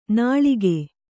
♪ nāḷige